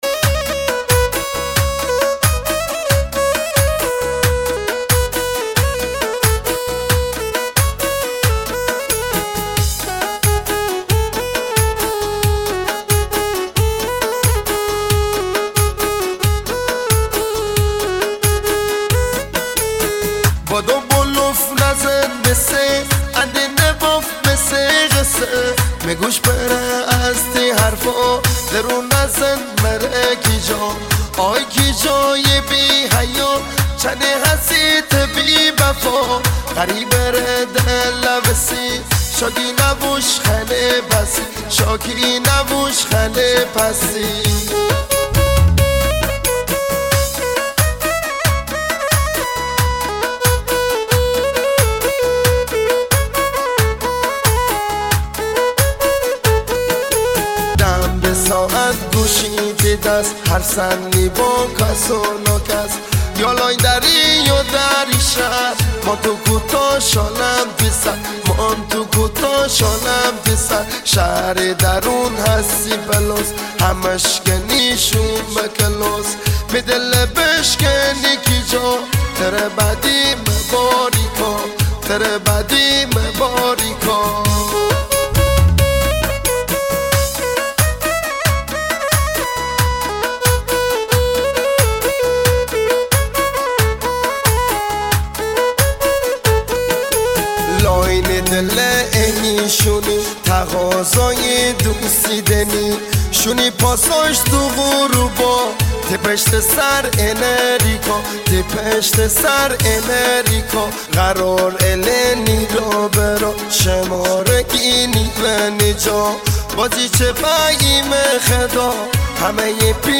آهنگ مازندرانی
آهنگ شاد